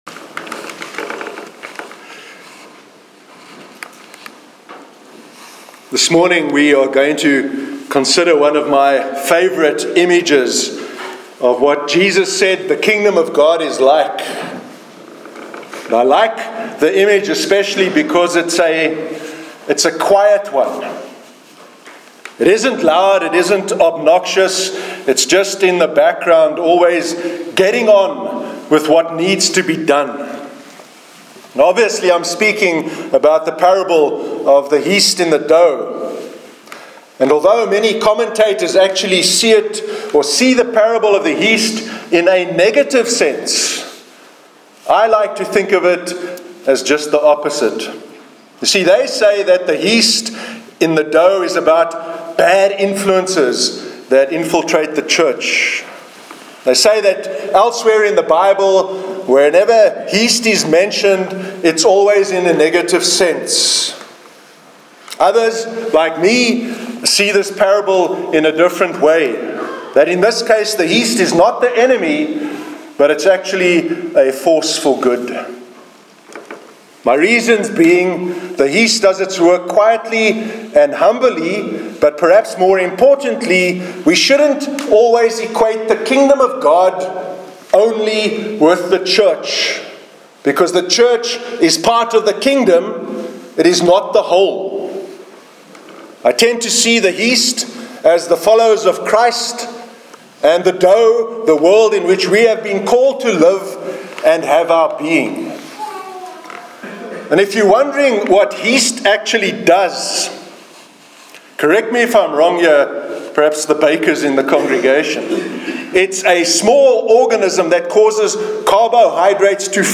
Sermon on the Parable of the Yeast in the Dough- 24th September 2017